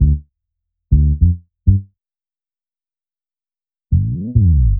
FK100BASS2-L.wav